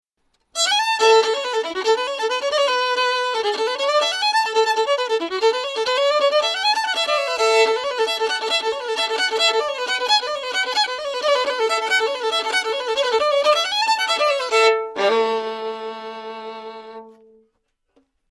Žánr: Bluegrass.